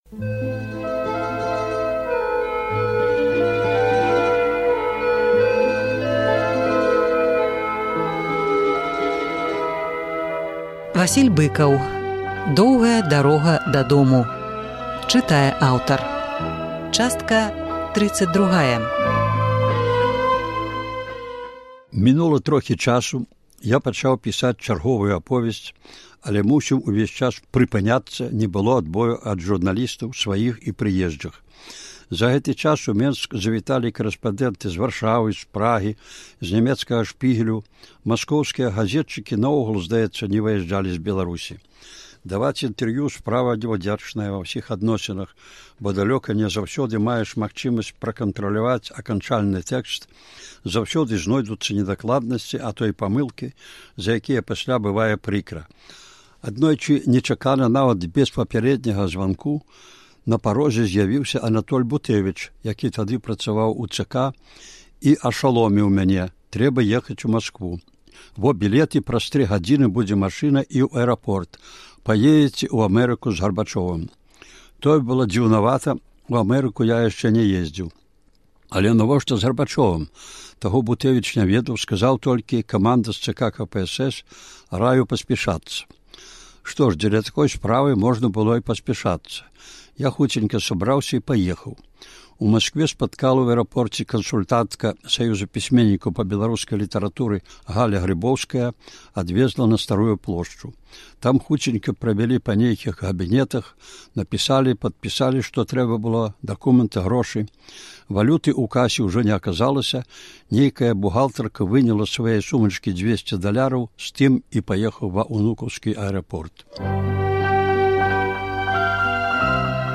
Успаміны Васіля Быкава «Доўгая дарога дадому». Чытае аўтар.